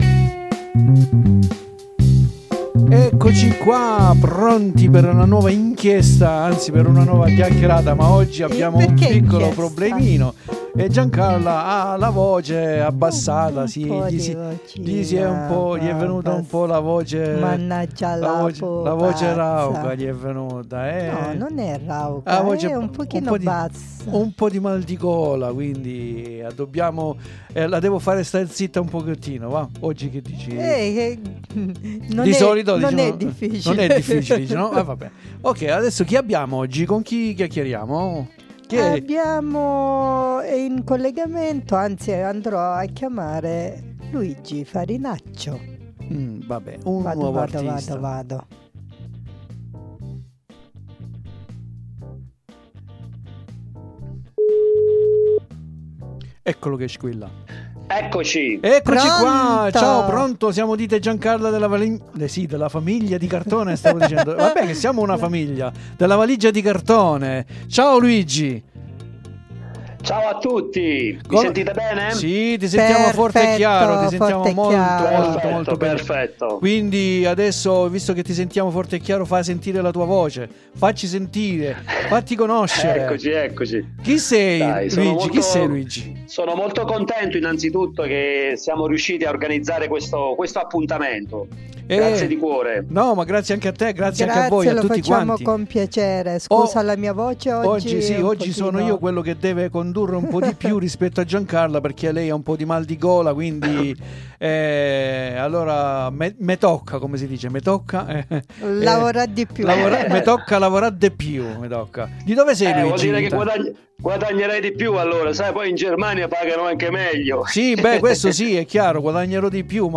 VI ALLEGO INOLTRE LA SUA INTERVISTA IN DESCRIZIONE E VI AUGURO UN BUON ASCOLTO!